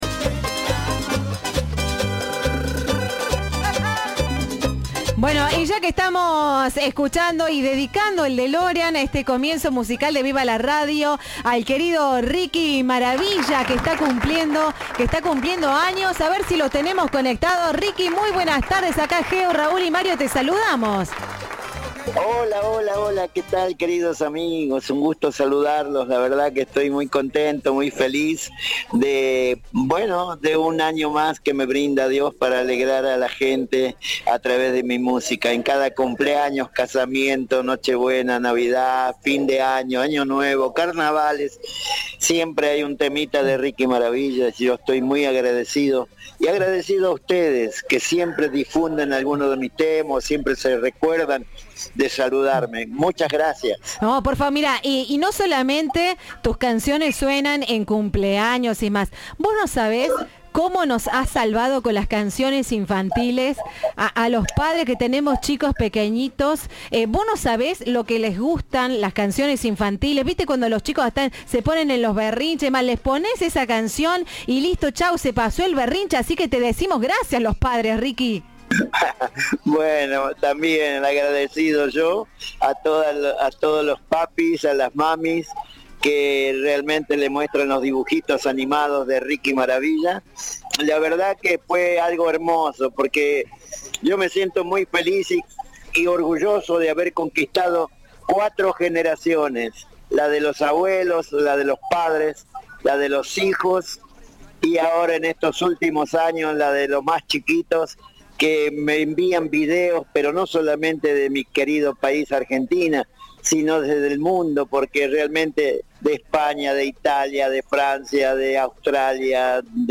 El emblema de la música tropical habló con Cadena 3. Mis canciones tienen un doble sentido sano, las pueden cantar toda la familia, dijo en una entrevista con Viva la Radio.